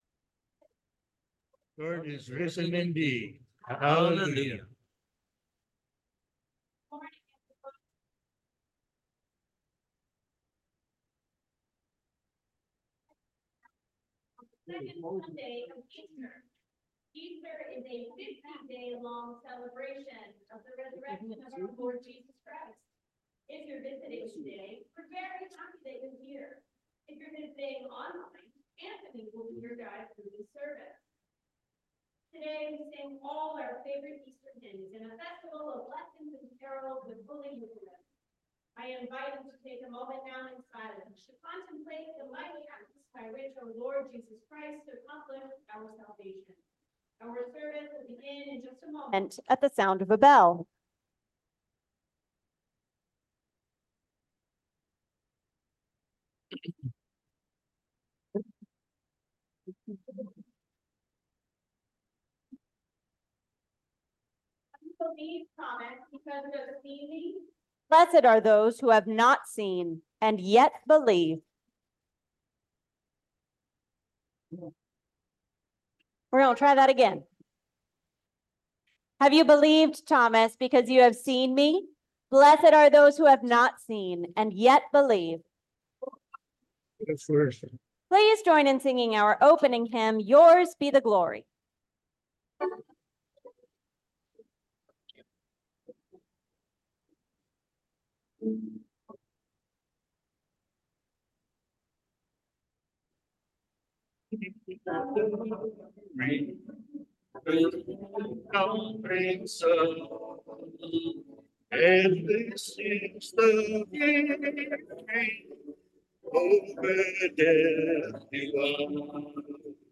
Celebrate the Easter Season with us on the Second Sunday of Easter. Our Music Team has some of your favourite hymns prepared for our Easter Lessons and Carols service. We hope you enjoy this recording of our sevice on April 7, 2024.